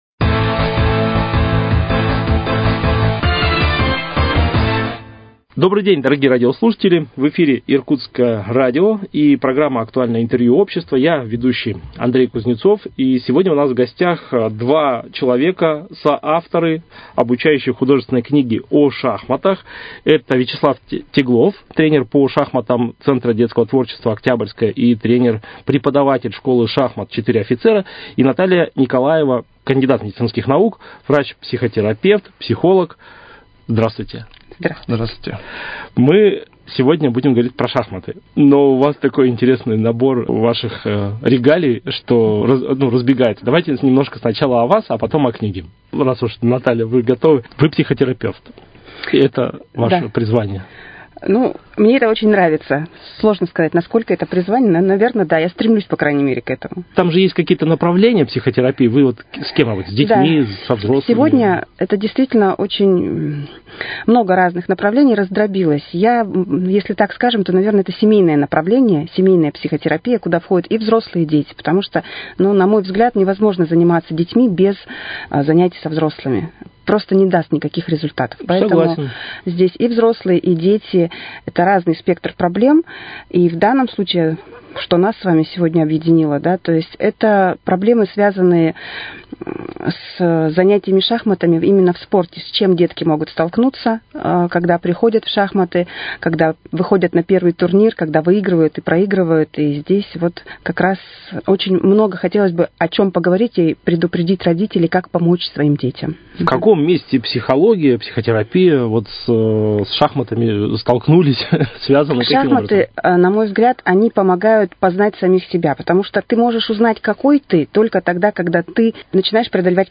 Актуальное интервью
Сегодня в студии Иркутского радио